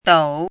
怎么读
dǒu
陡 [dǒu]